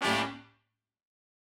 GS_HornStab-A7b2sus4.wav